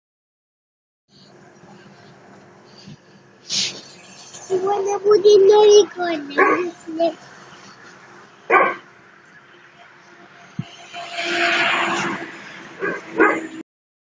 Звуки КПК, ПДА из Сталкера (S.T.A.L.K.E.R.)
• Качество: Высокое